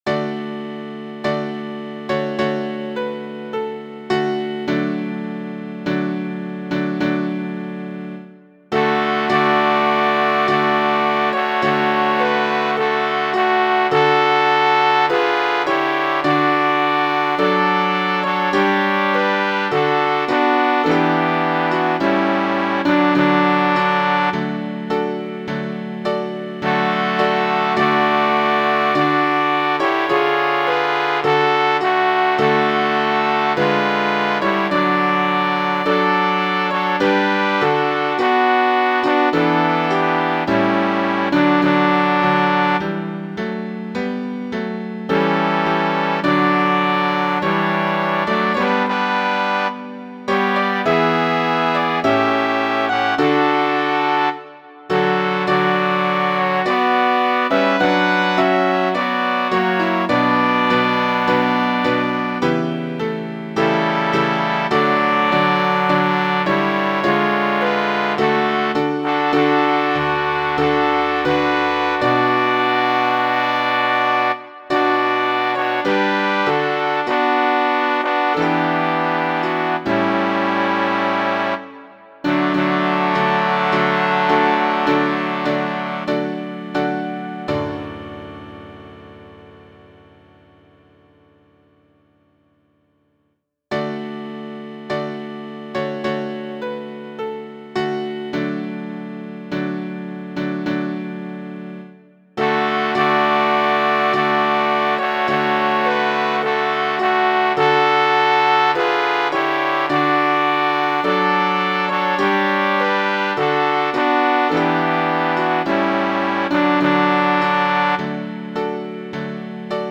Title: When he, who adores thee Composer: Anonymous (Traditional) Arranger: Michael William Balfe Lyricist: Thomas Moore Number of voices: 4vv Voicing: SATB Genre: Secular, Partsong, Folksong
Language: English Instruments: A cappella